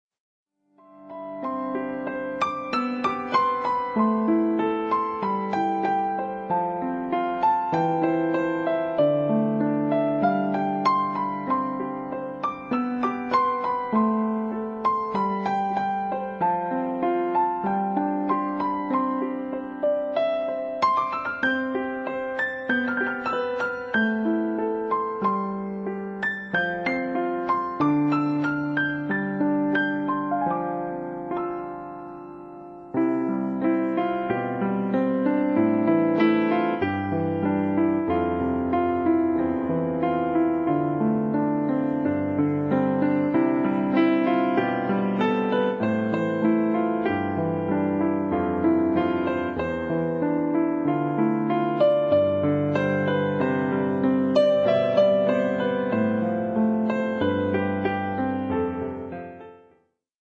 Không Lời (Piano)